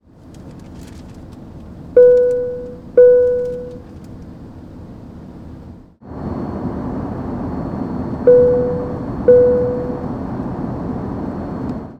Fasten Seatbelt Sound
airplane air-travel beep ding ding-dong dong fasten fasten-seat-belt sound effect free sound royalty free Sound Effects